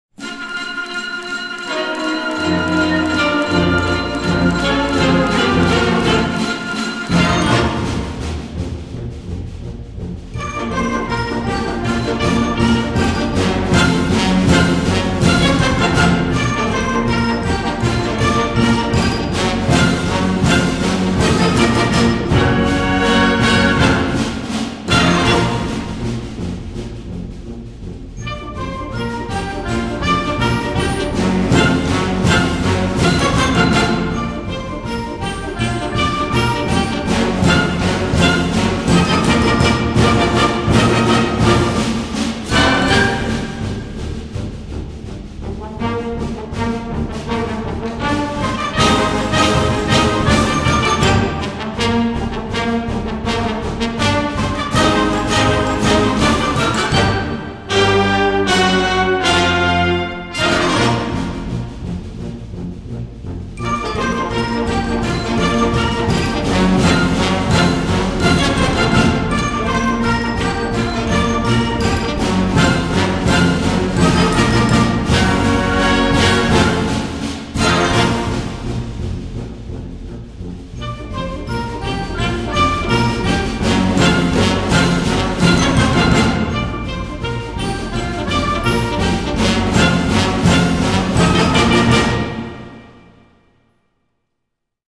S C H O O L   C O N C E R T   B A N D S